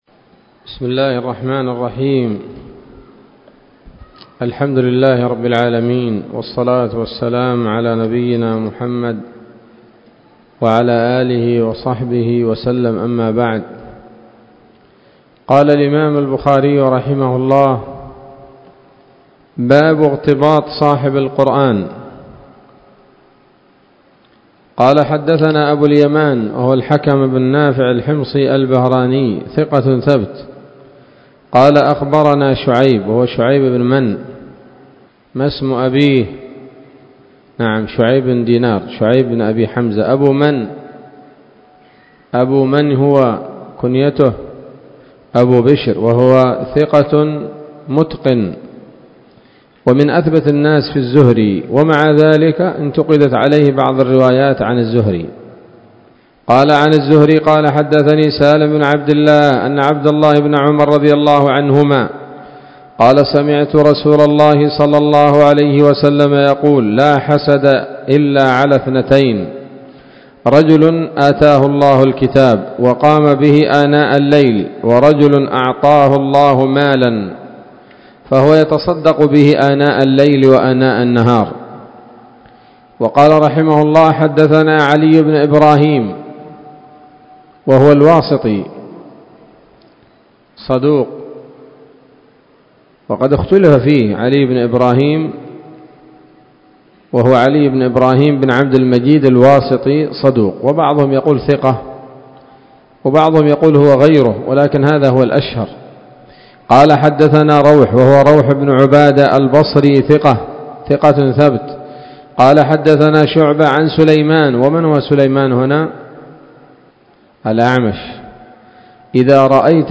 الدرس الحادي والعشرون من كتاب فضائل القرآن من صحيح الإمام البخاري